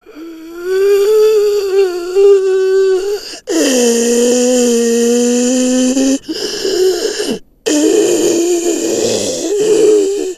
Grannys Zombie-Röcheln für euch als Klingelton:
klingelton-zombie-granny-1604.mp3